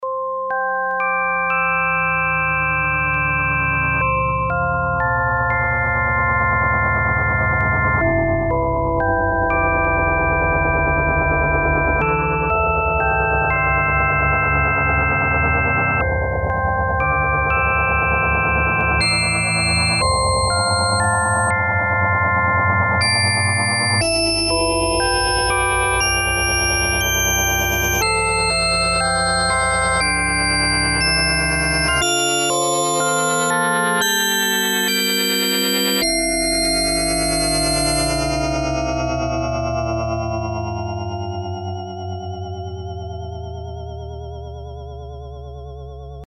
BELLS: dual chorused
bells-dual-dx21-chorus.mp3